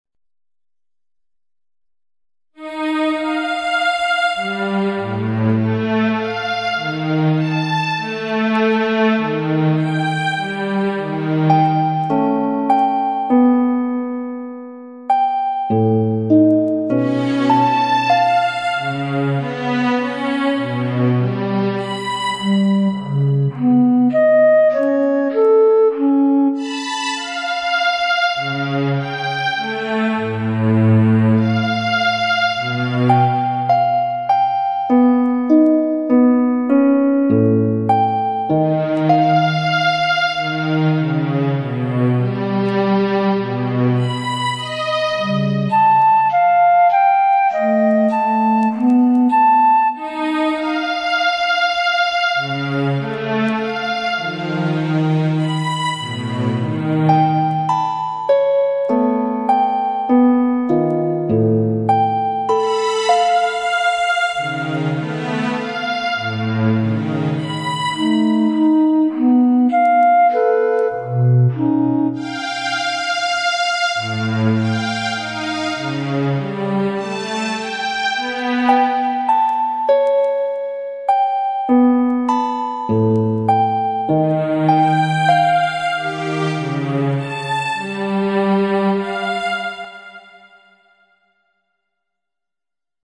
Again you will hear the four calcium sites in the harp and the three helical segments in strings.